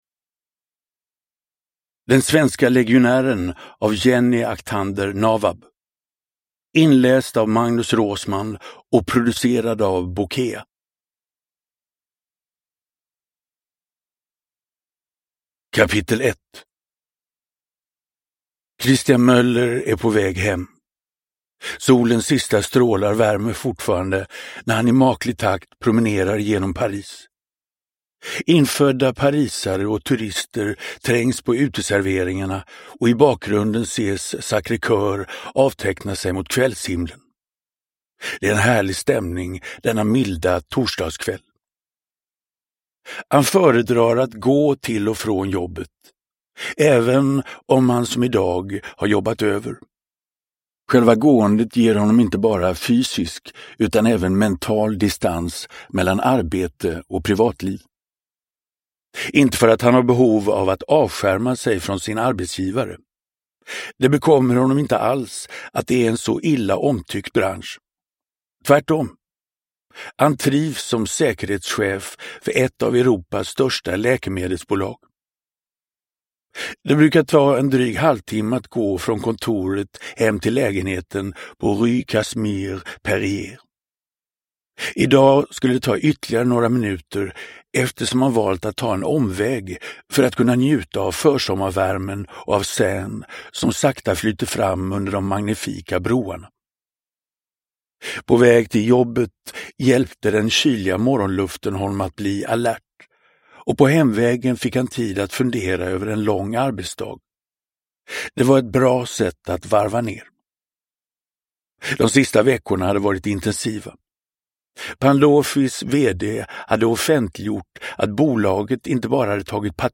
Den svenske legionären (ljudbok) av Jenny Navab